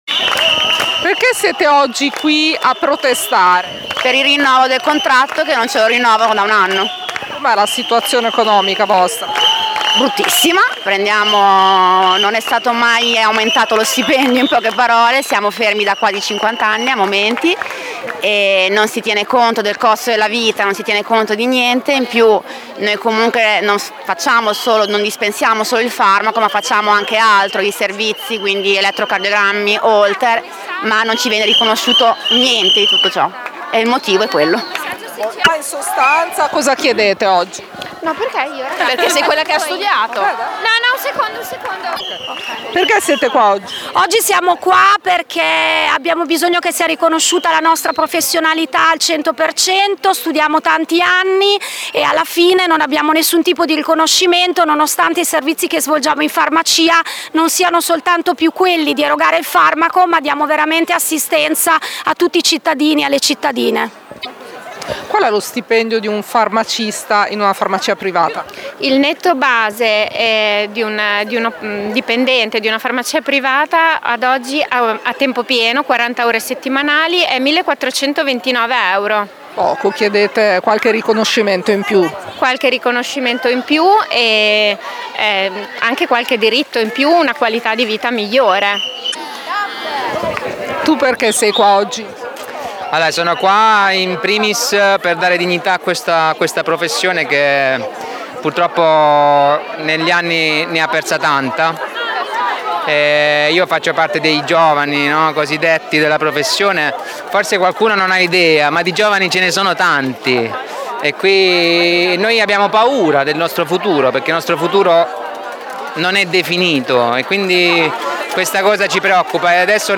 A Modena lo sciopero interessa circa 150 farmacie quasi 500 addetti. Hanno organizzato un presidio davanti alla sede di Federfarma in strada degli Schiocchi, 42. Ascolta le interviste